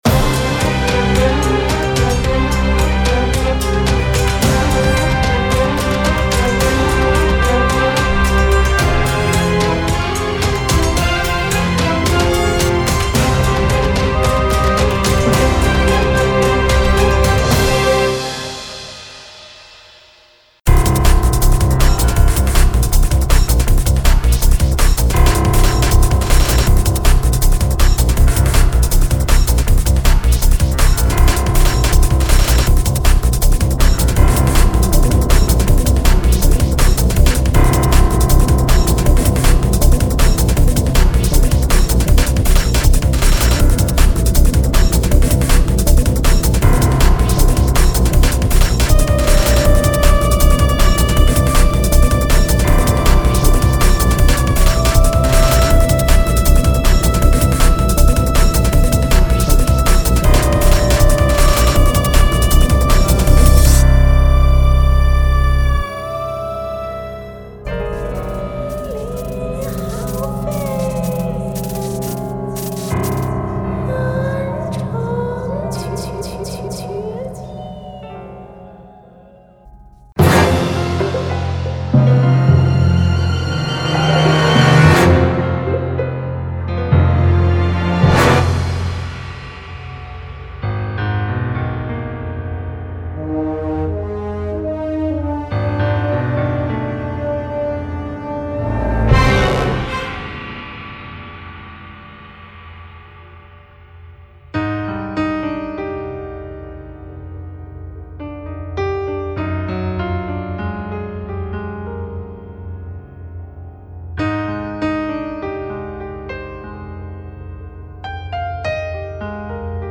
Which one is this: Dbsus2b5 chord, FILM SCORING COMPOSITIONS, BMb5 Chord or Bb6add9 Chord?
FILM SCORING COMPOSITIONS